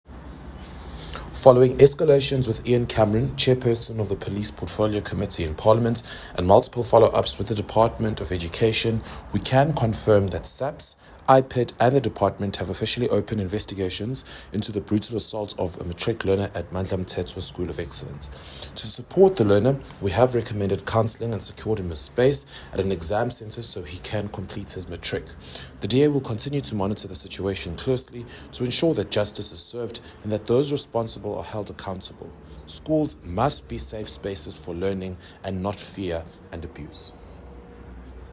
Issued by Sakhile Mngadi, MPL – DA KZN Spokesperson on Education
Note to Editors: Please note Sakhile Mngadi, MPL sound bite in